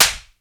Claps
SLAP   2.WAV